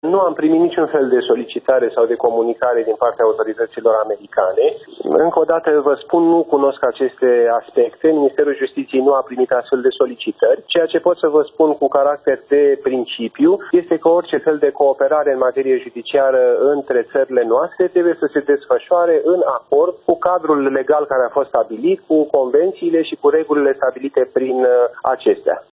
Radu Marinescu, la televiziunea publică: „Ministerul Justiției nu a primit astfel de solicitări”